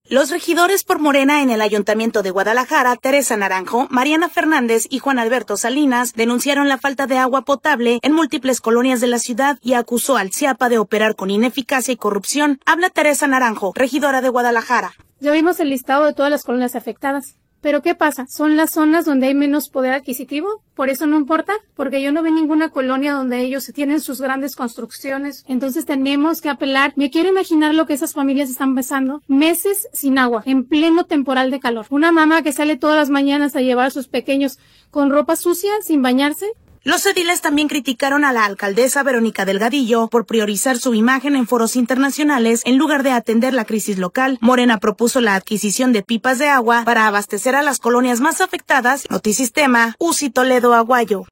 Los regidores por Morena en el Ayuntamiento de Guadalajara, Teresa Naranjo, Mariana Fernández y Juan Alberto Salinas, denunciaron la falta de agua potable en múltiples colonias de la ciudad y acusó al SIAPA de operar con ineficacia y corrupción. Habla Teresa Naranjo, regidora de Guadalajara.